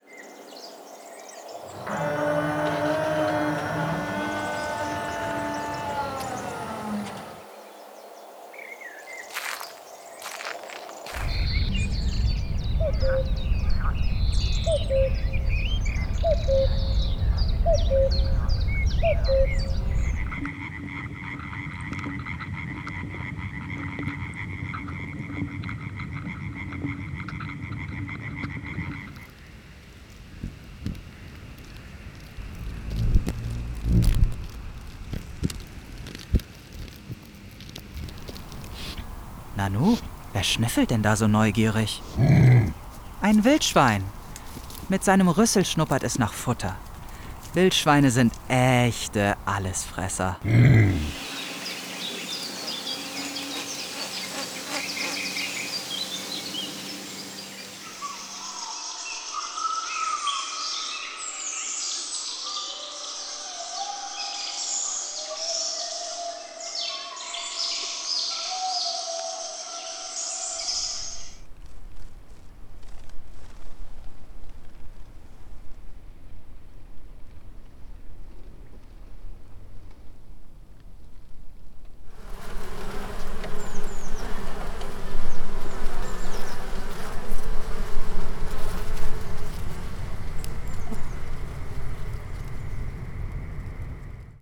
Runtergepitchte Fledermäuse und zweizylindrige Libellen
Gegenstand und Geräuschquelle ist alles, was sie auch zuvor akustisch erforscht haben: Bäume, Vogelstimmen und Insekten, Bienen, das Erdreich oder die Unterwasserwelt.
Klicken Sie aufs Foto!